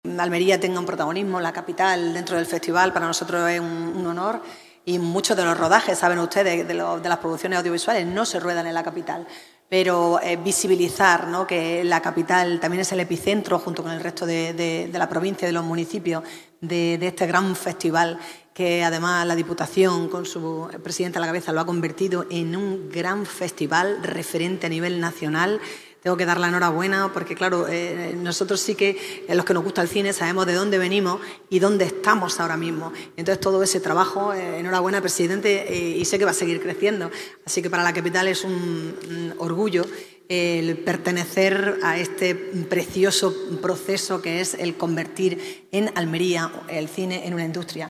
El Patio de Luces de Diputación ha acogido la presentación de la XXIV edición de FICAL, impulsado por la Institución Provincial, con el apoyo del Ayuntamiento de Almería y la Junta de Andalucía
ALCALDESA-PRESENTACION-FICAL-2025.mp3